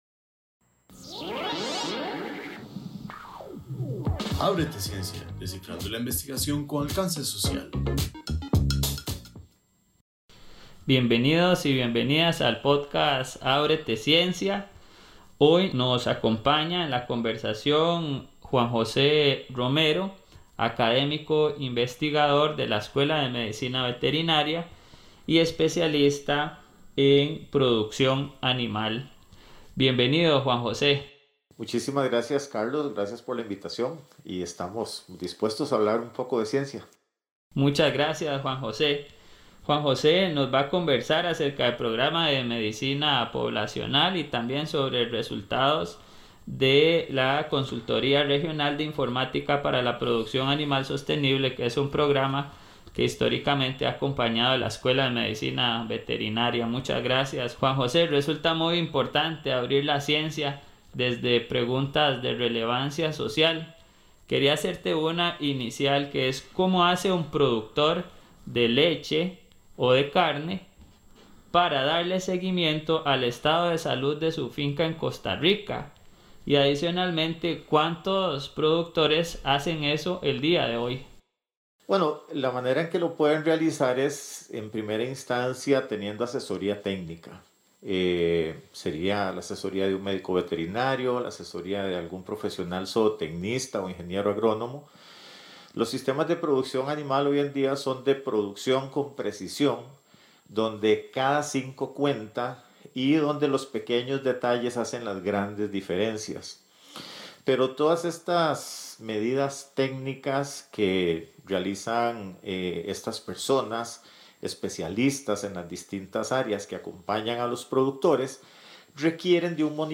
¡No te pierdas esta fascinante conversación sobre la ciencia y su impacto en nuestra sociedad!